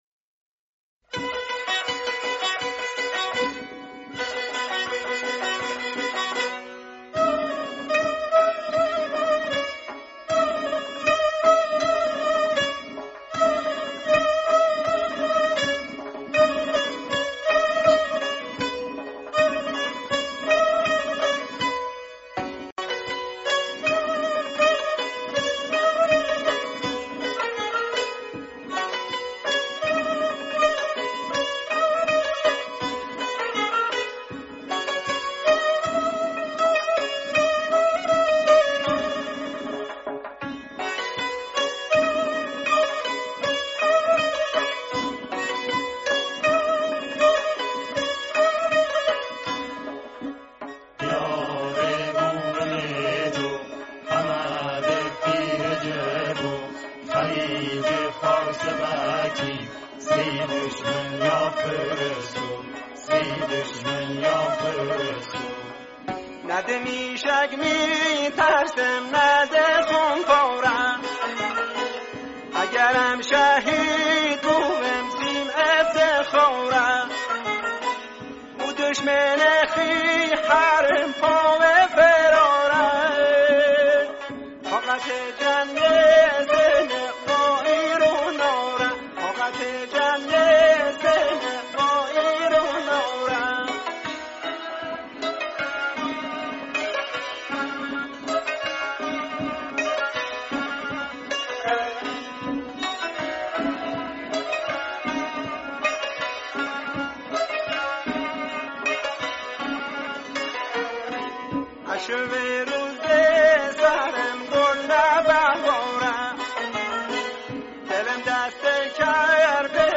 تصنیف لری ساخته آیت ‌الله گودرزی سال ۱۳۶۷ /شیوه اجرا اركستر